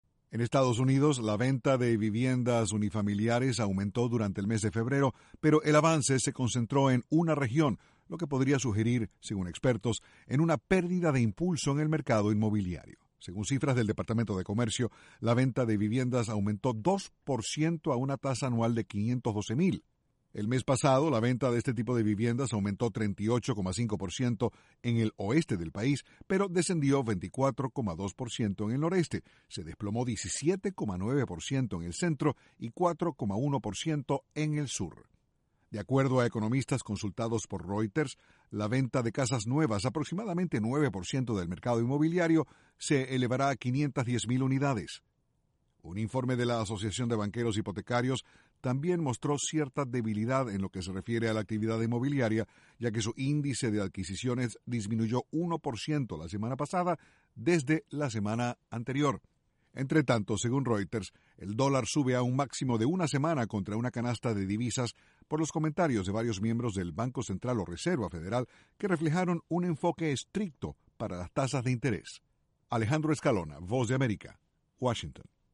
Aumenta venta de viviendas en Estados Unidos. Desde la Voz de América, Washington